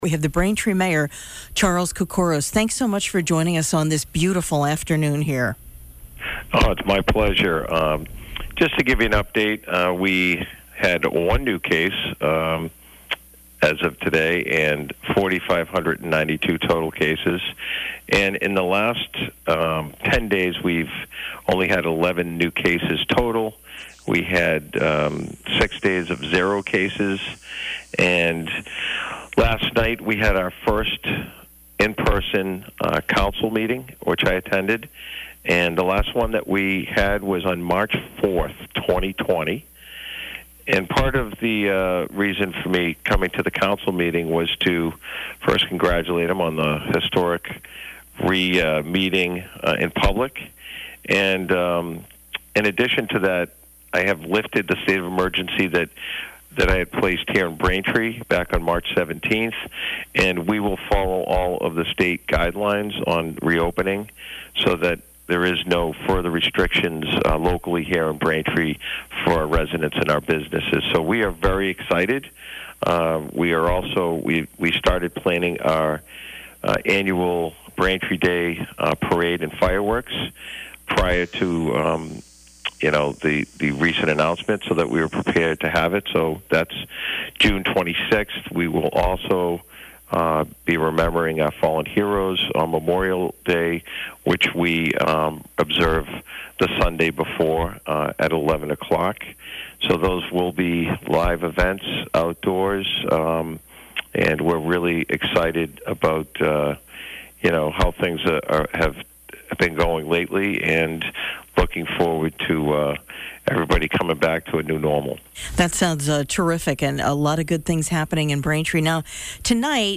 Braintree Mayor Discusses COVID, Recovery Plan